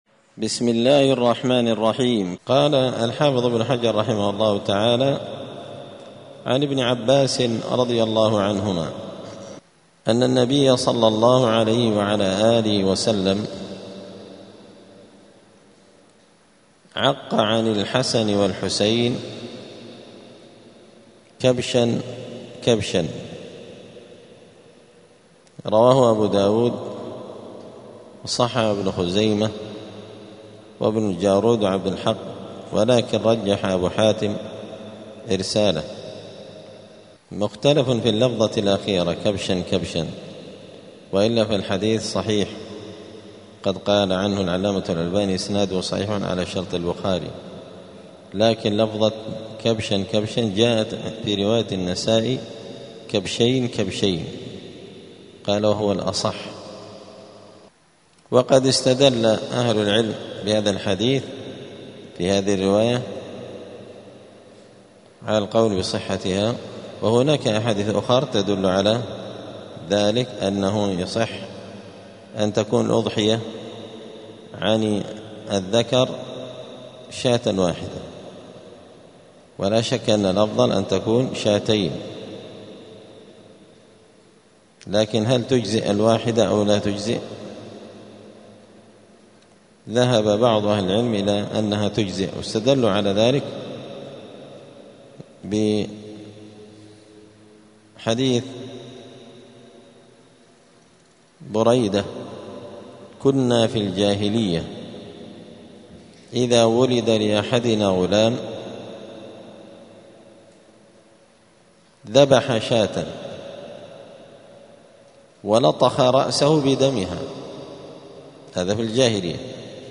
*الدرس السابع والعشرون (27) {باب العقيقة}*